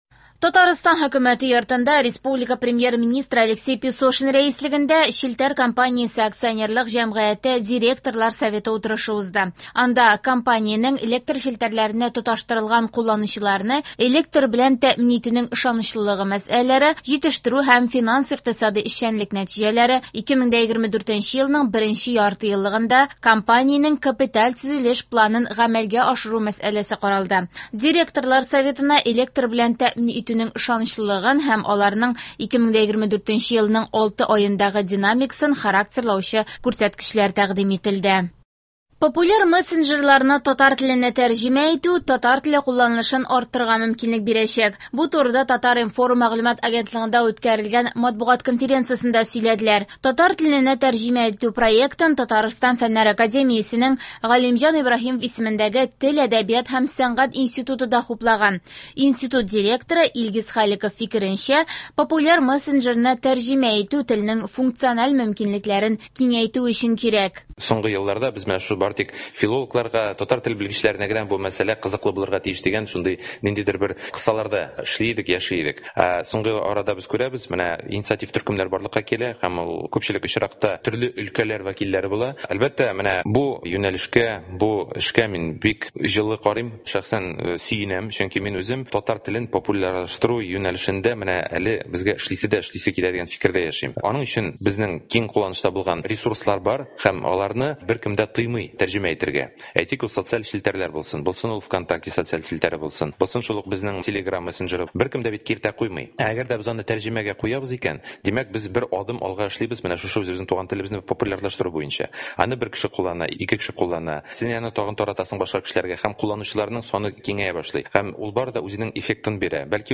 Яңалыклар (11.09.23)